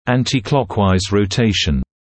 [ˌæntɪ’klɔkwaɪz rə’teɪʃn][ˌэнти’клокуайз рэ’тэйшн]вращение против часовой стрелки